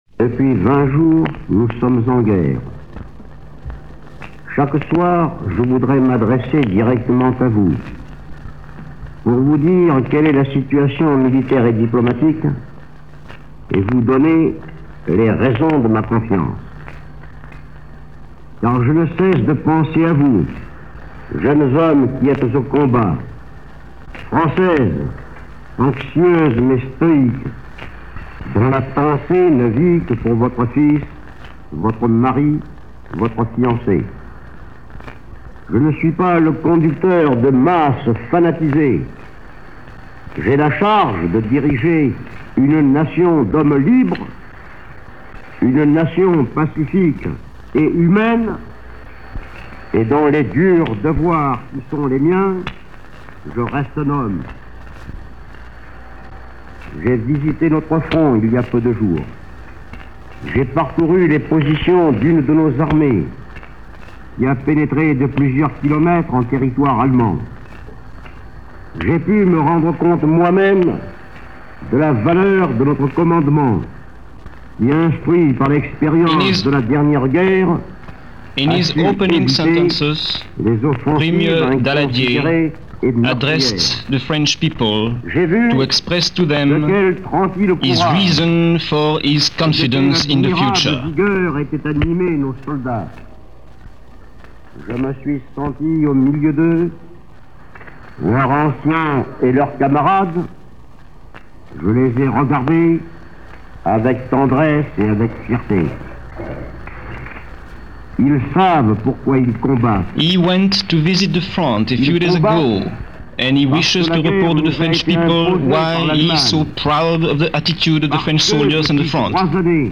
An Address By Edouard Daladier - September 21, 1939 - broadcast live to the world by Paris Radio - September 21, 1939.
Prime Minister Edouard Daladier – A pep talk in time of war.